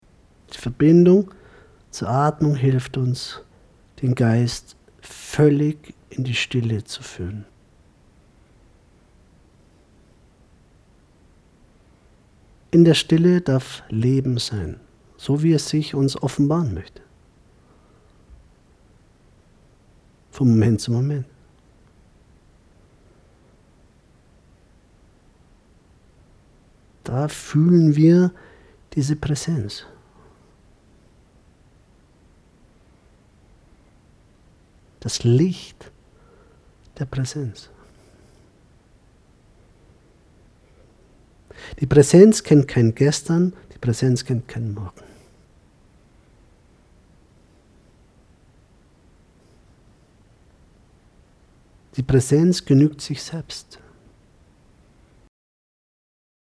Meditation Live-Aufnahme